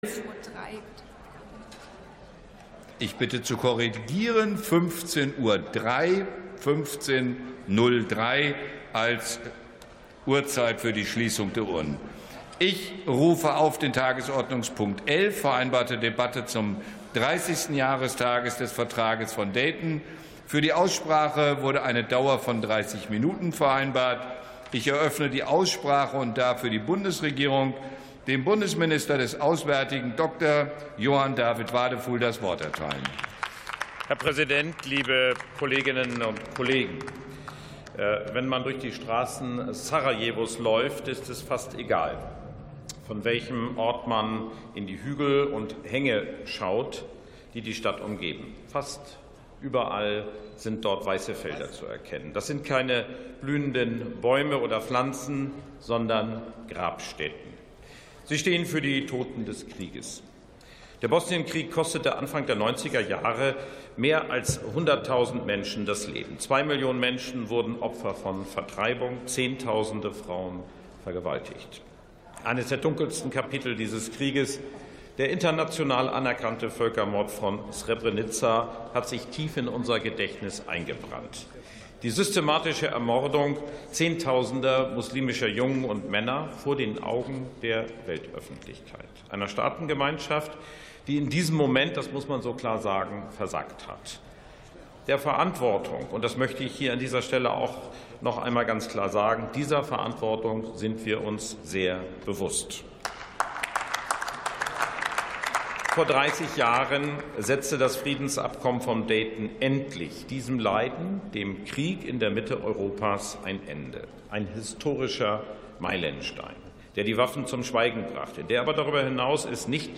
48. Sitzung vom 05.12.2025. TOP 11: 30. Jahrestag des Vertrags von Dayton ~ Plenarsitzungen - Audio Podcasts Podcast